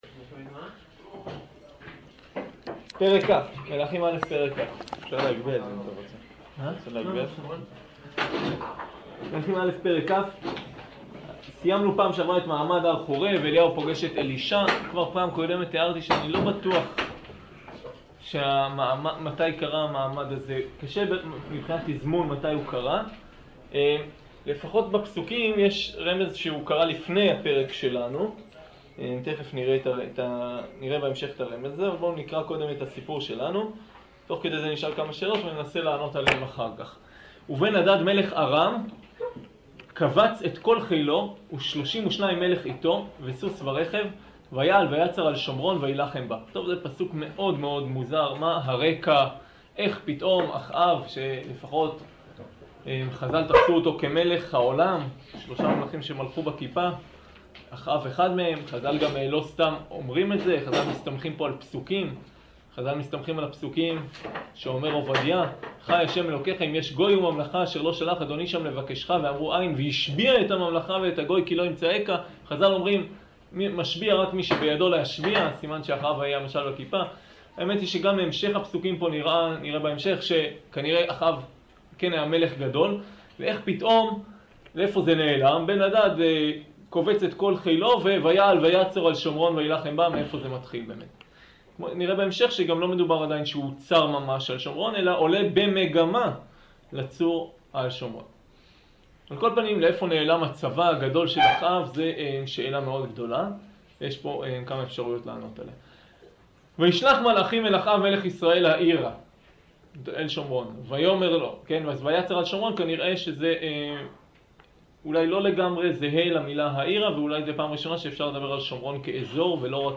שיעור מלכים